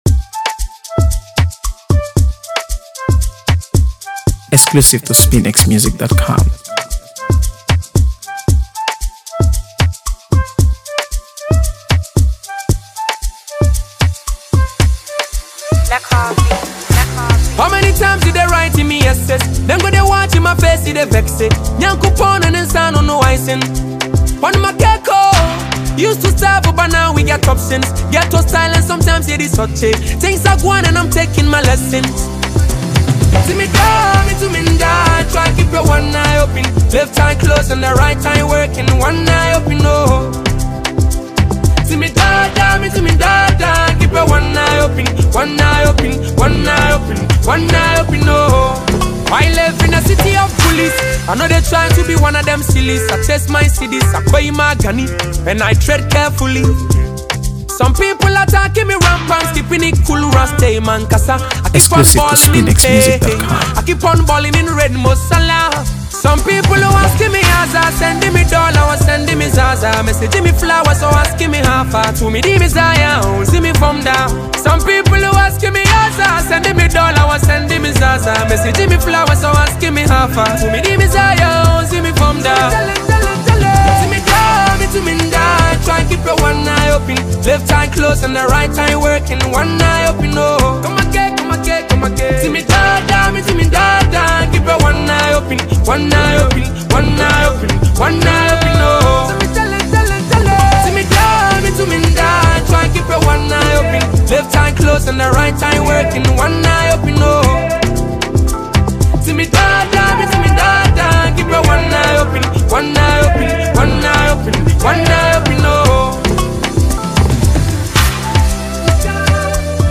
AfroBeats | AfroBeats songs
a gripping and soulful track